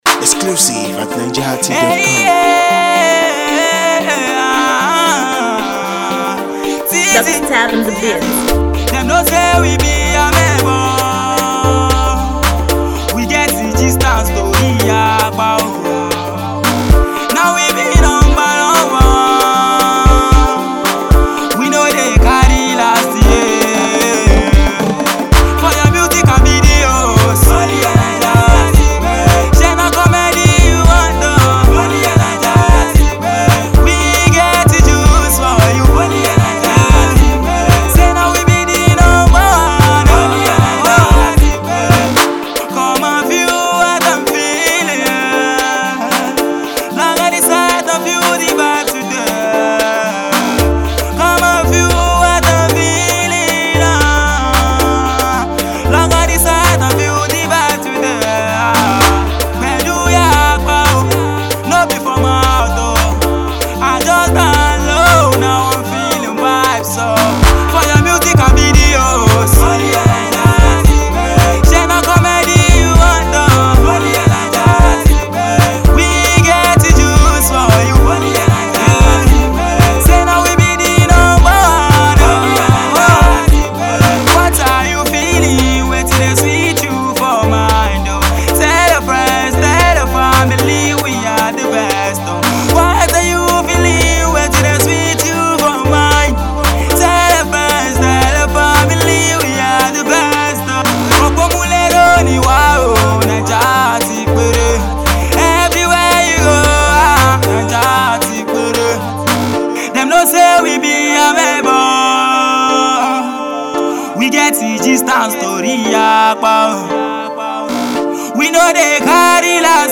multi-Talented singer
crooner